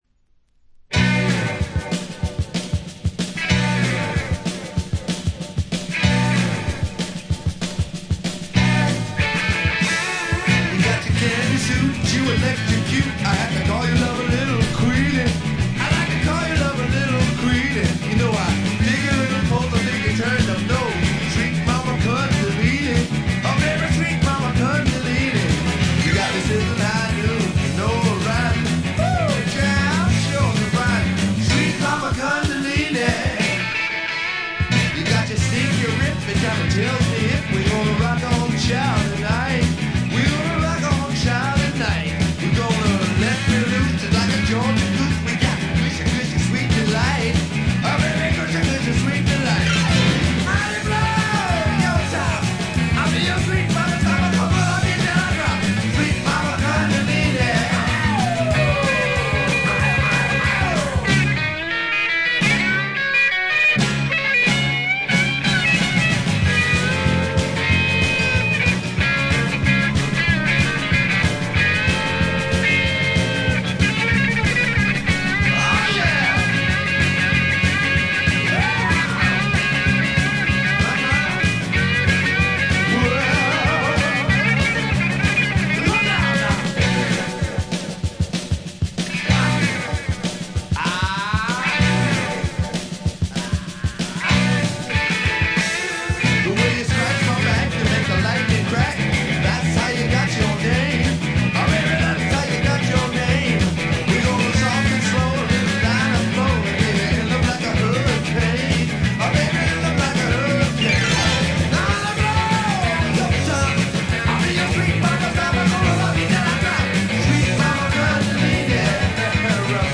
部分試聴ですが、ごくわずかなノイズ感のみ。
これはもう最高のR&B／ロックンロールと言うしかないでしょう。
試聴曲は現品からの取り込み音源です。